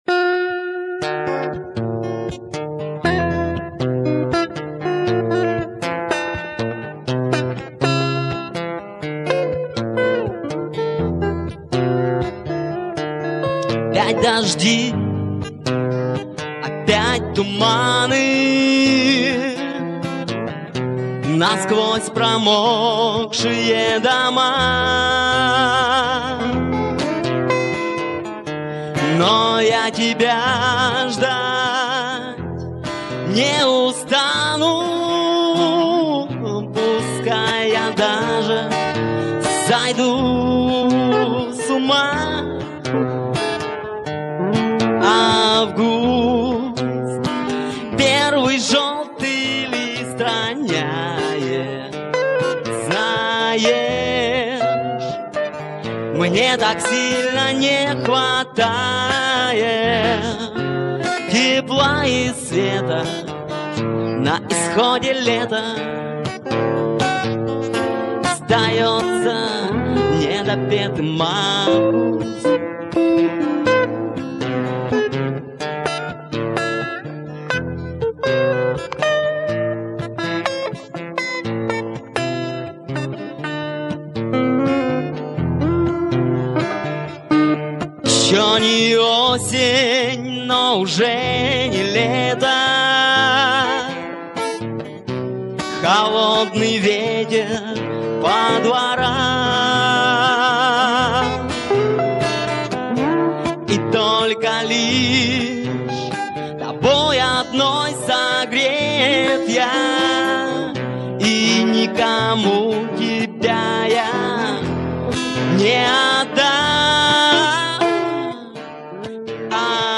(2,51 Mb, 96Kbps, Live, 2003)